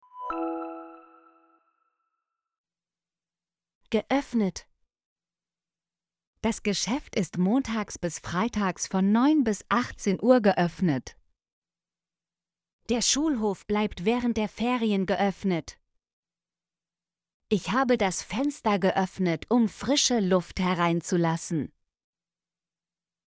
geöffnet - Example Sentences & Pronunciation, German Frequency List